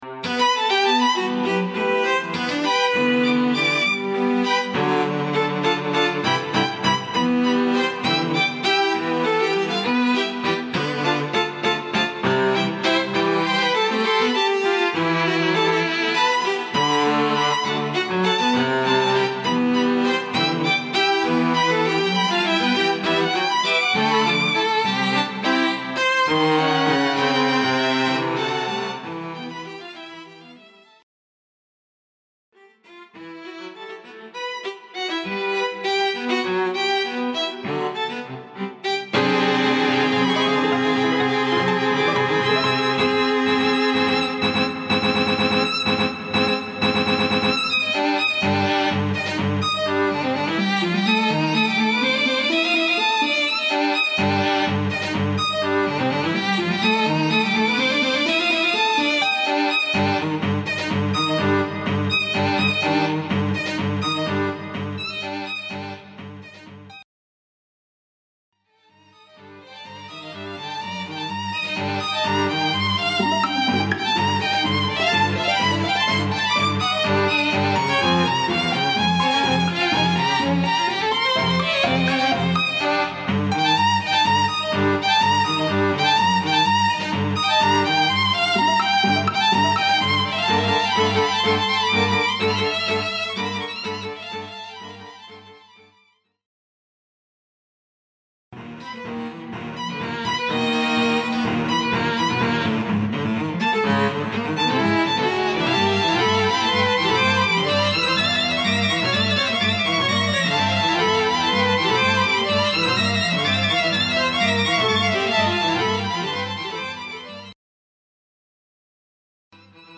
The re-occurring heartbeat, finally stops.